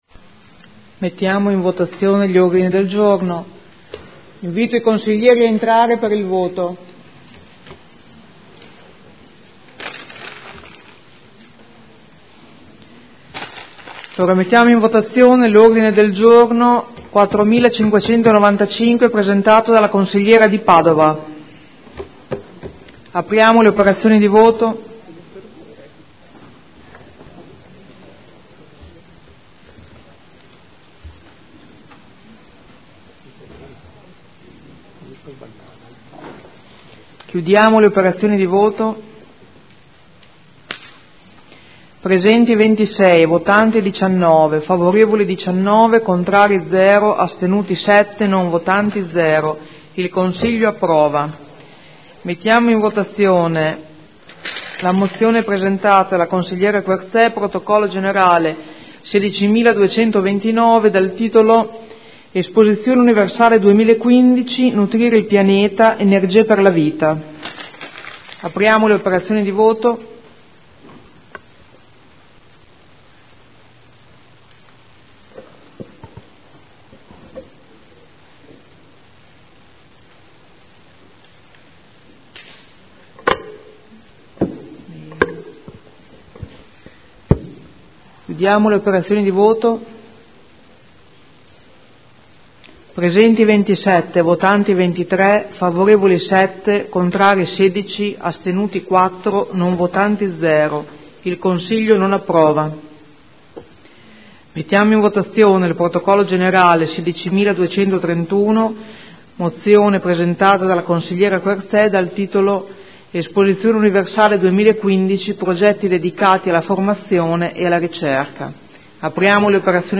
Seduta del 09/02/2015. Votazione degli ordini del giorno/mozioni inerenti l'expo 2015